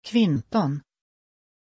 Pronunciation of Quinton
pronunciation-quinton-sv.mp3